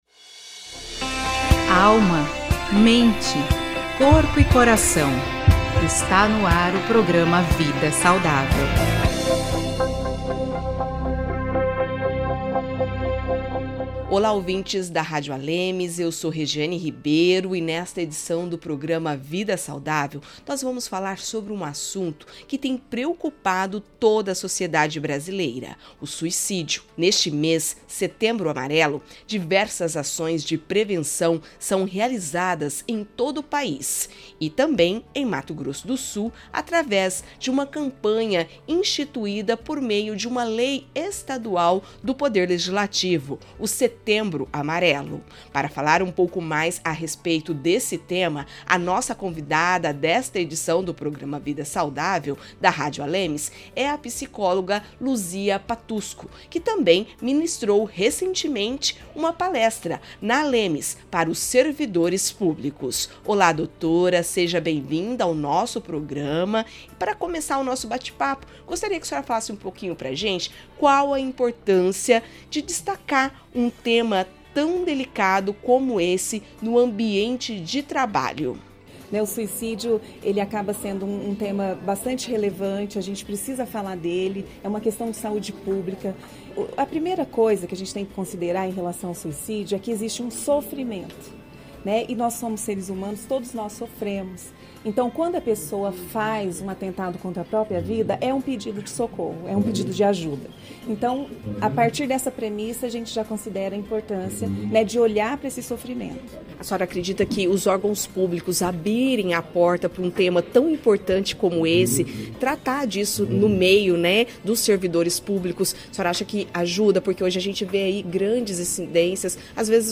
Setembro Amarelo: Psicóloga fala da importância de pedir ajuda